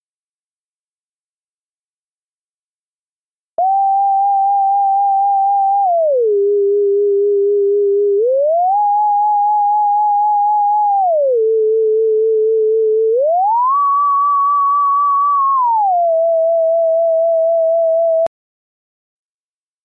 En cada unha das preguntas atoparedes un arquivo de audio que contén unha secuencia de son, é dicir, unha onda que vai subindo ou baixando de maneira concreta en cada caso.